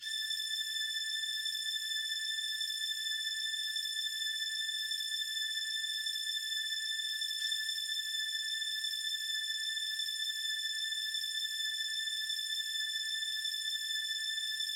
描述：花花公子类型的节拍东西。用这个字符串来填补背景的空虚。
Tag: 130 bpm Hip Hop Loops Strings Loops 2.48 MB wav Key : Unknown FL Studio